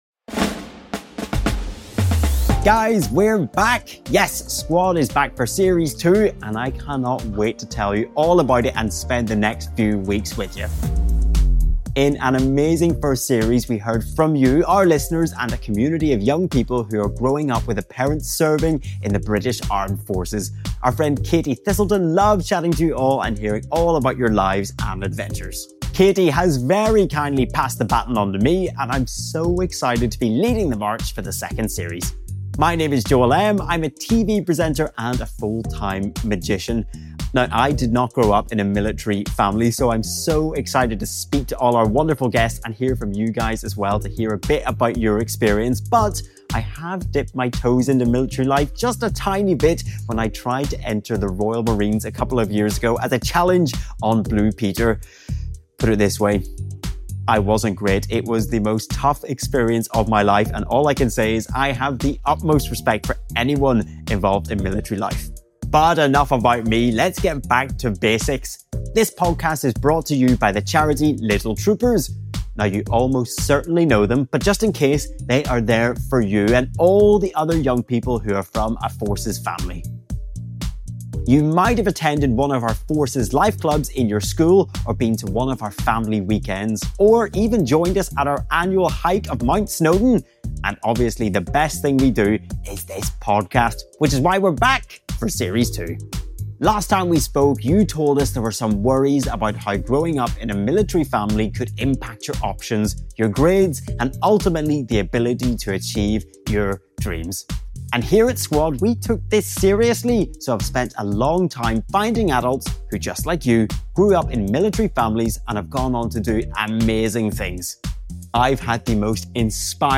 chats to Michelin Star Chef & Restaurateur Adam Handling MBE